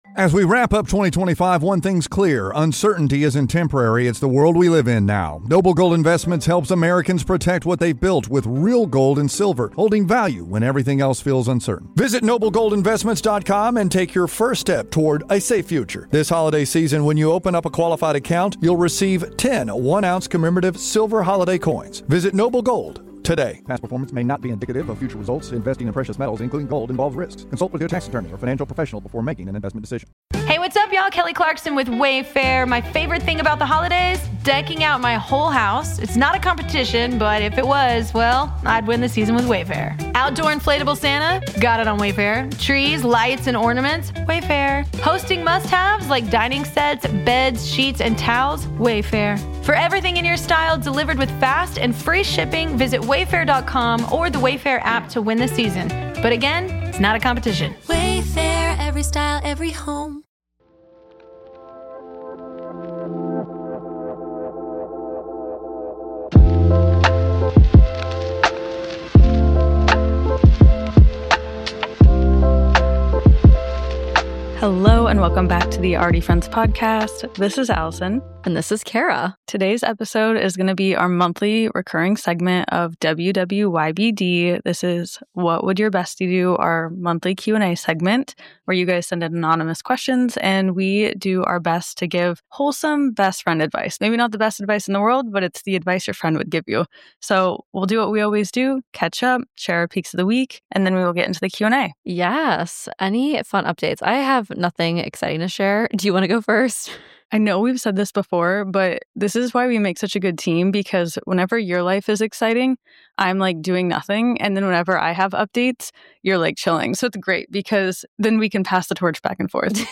Already Friends is your weekly dose of wellness and real talk, designed to help you elevate your well-being one episode at a time. Hosted by two best friends, we explore topics on personal growth, self-care, and building meaningful relationships—all while fostering a community of like-minded listeners around the world.